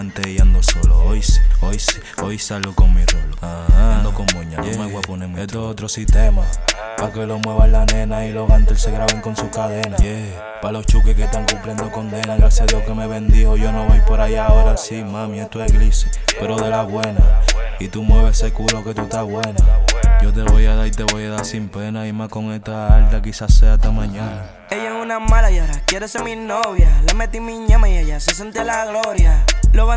Latin Rap Hip-Hop Rap
Жанр: Хип-Хоп / Рэп